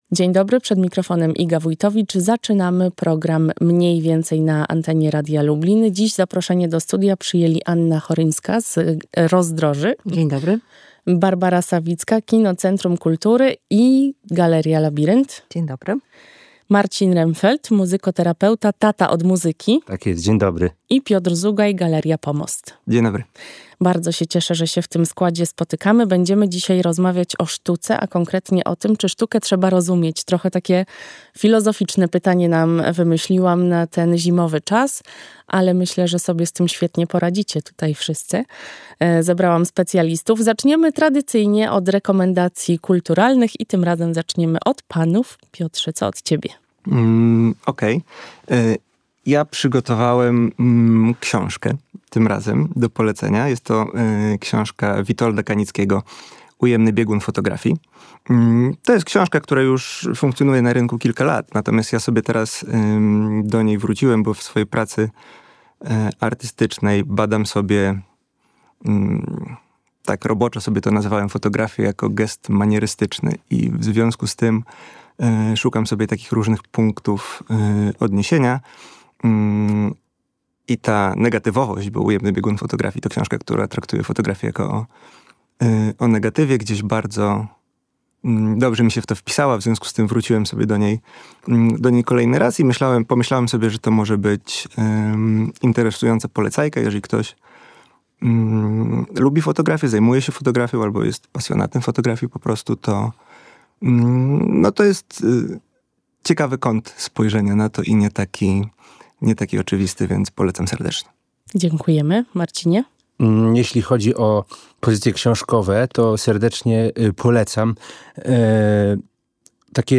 Na rozmowę tuż po godzinie 17.00 zaprasza